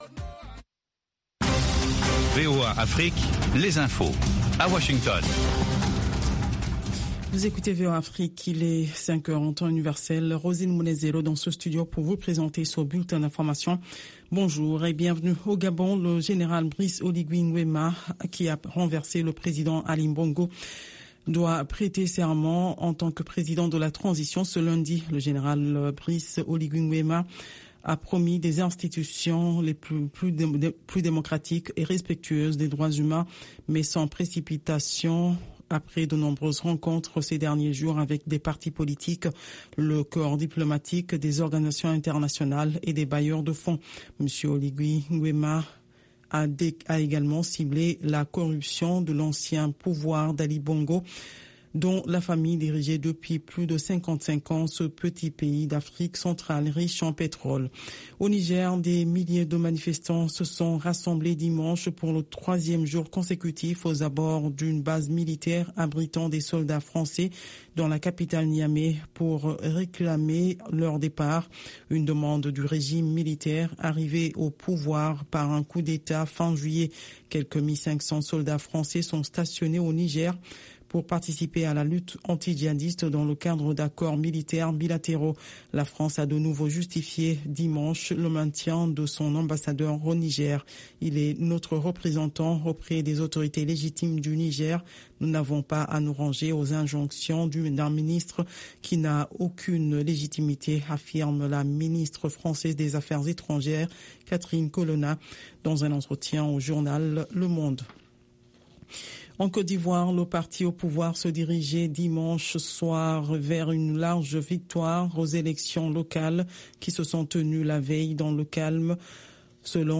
Bulletin
5 min News French